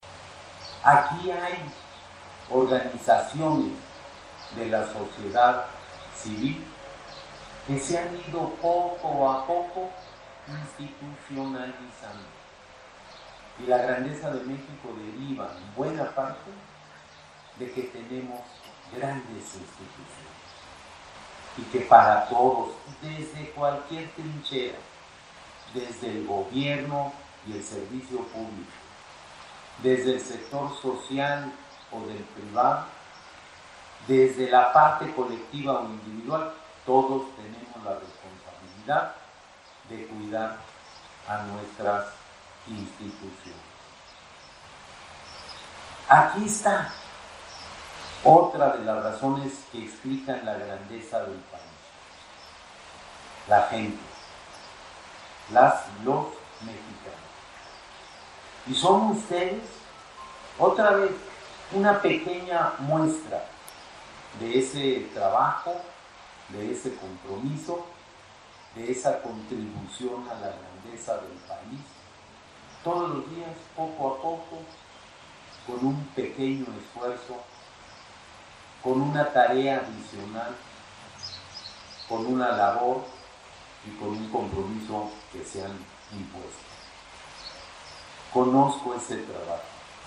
Al presidir en representación del Presidente Enrique Peña Nieto, la ceremonia de entrega del 11° Premio Nacional de Acción Voluntaria y Solidaria 2018, la cual se llevó a cabo en el Patio Central de la Secretaría de Salud, Narro Robles reconoció y agradeció la labor de los voluntarios quienes de manera altruista ayudan y sirven a la gente, acción que consolida la grandeza del país.
El Secretario de Salud, José Narro Robles, presidió la ceremonia de entrega del 11° Premio Nacional de Acción Voluntaria y Solidaria 2018[audio